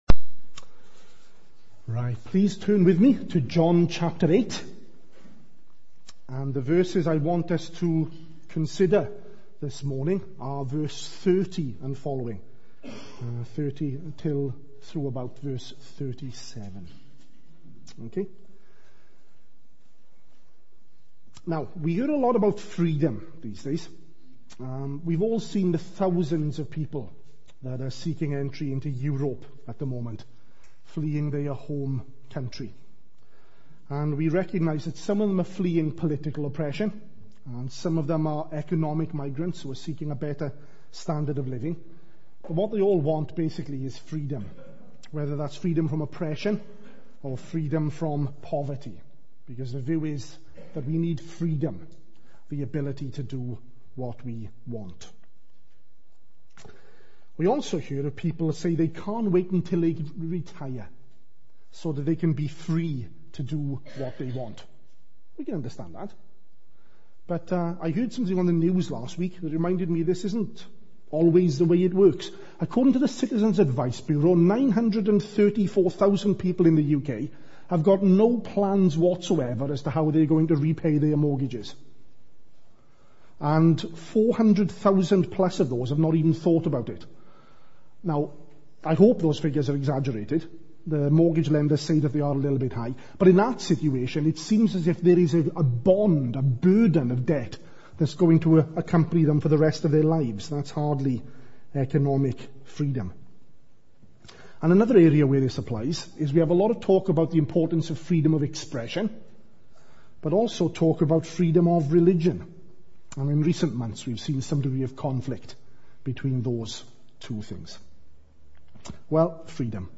at morning service